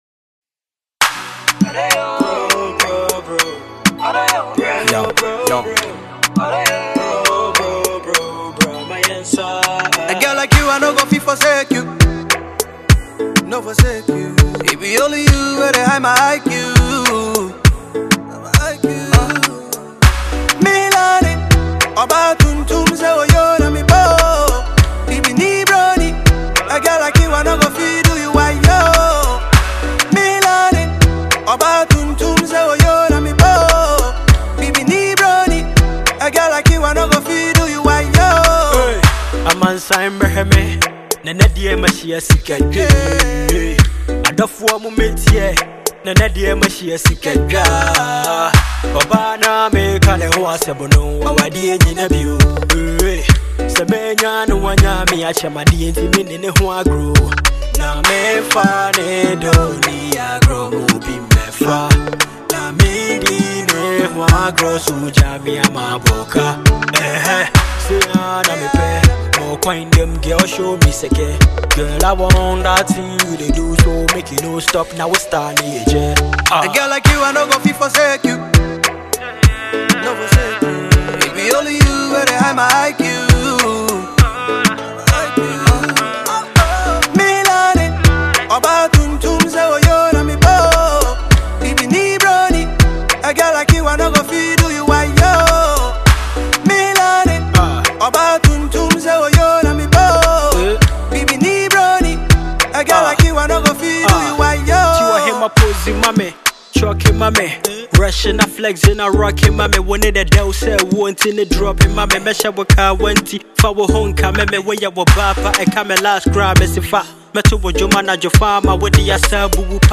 A highlife song